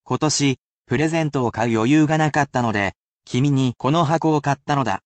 I can only read it at one speed, so there is no need to repeat after me, but it can still assist you in picking out vocabulary within natural speeds of speech.
[casual]